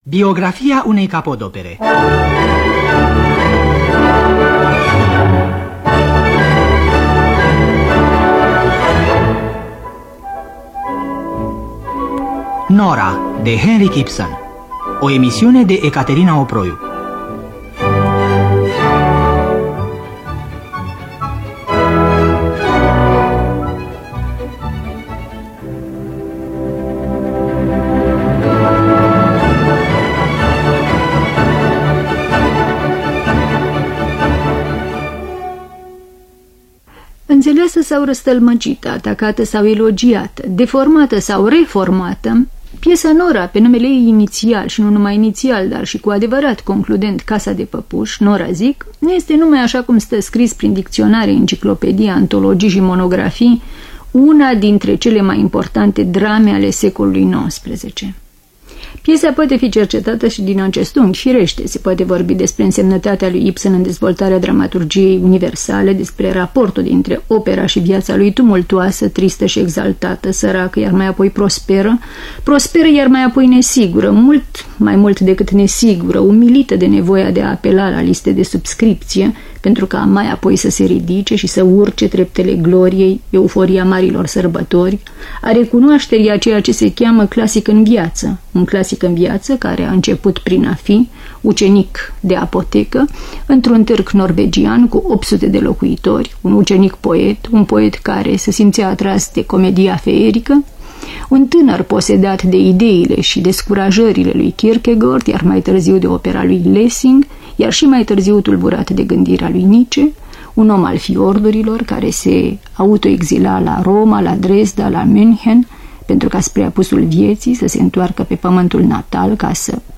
Scenariu radiofonic de Ecaterina Oproiu.